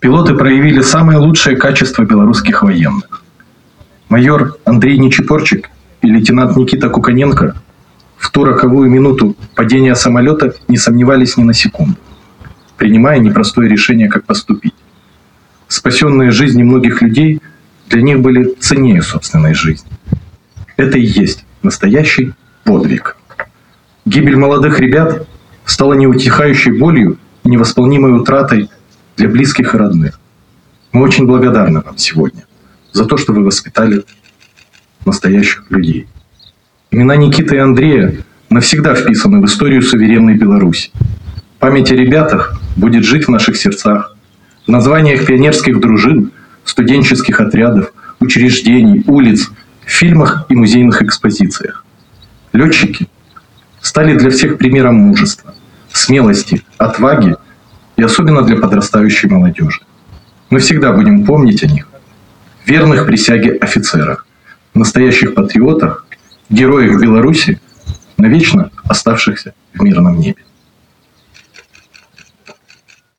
В Барановичах у мемориала «Назаўсёды у мірным небе» состоялся митинг, посвященный памяти погибших летчиков